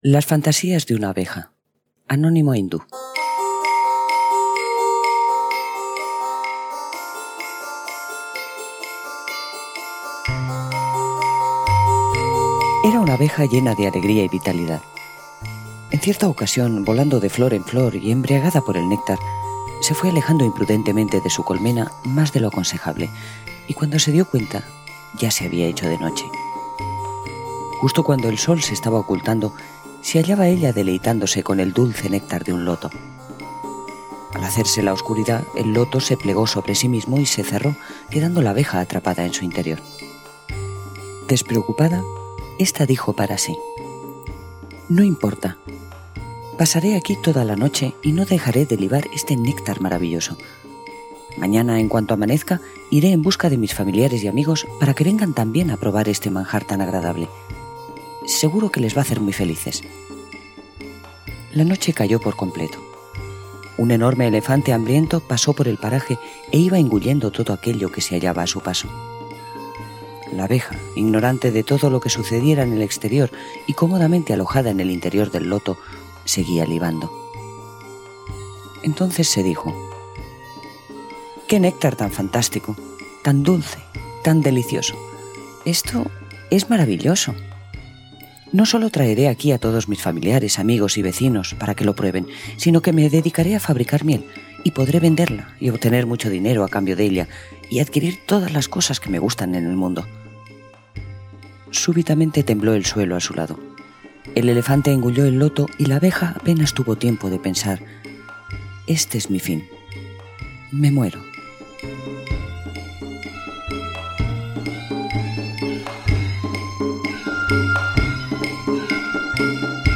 Audiolibro: Los sueños de una abeja
Música: Pamauni (cc:by-sa)